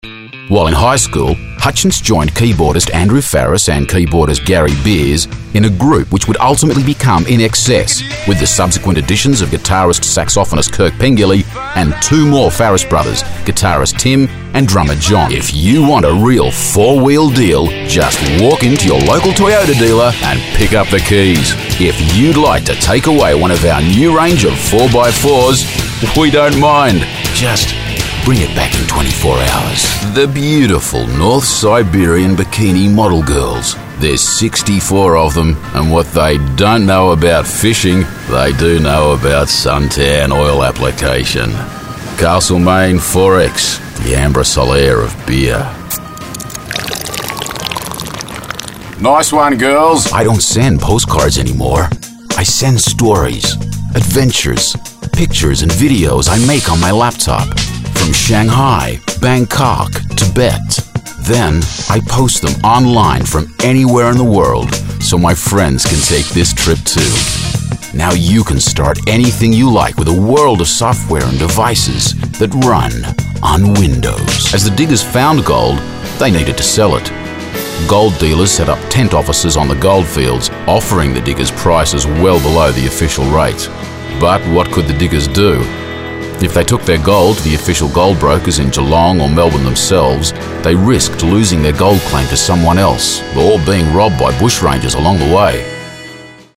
Commercial reel